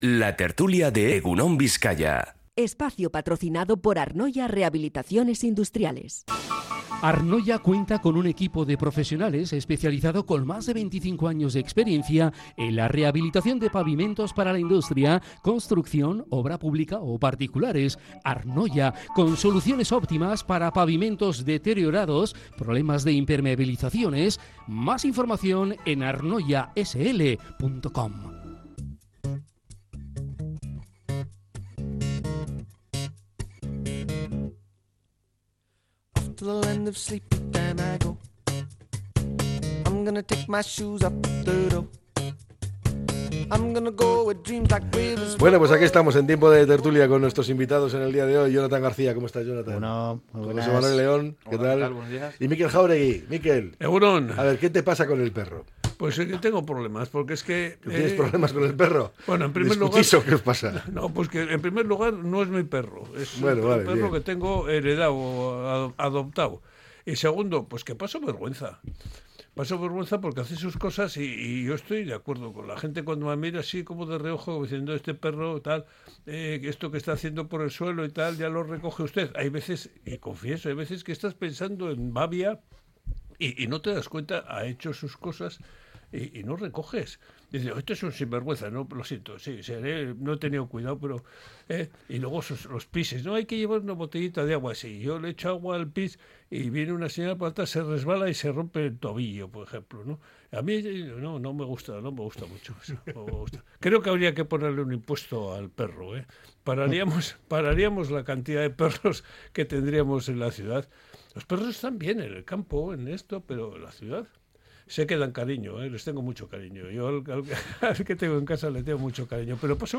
La tertulia 15-01-25. Escucha el podcast Pisos turísticos en Radio Popular.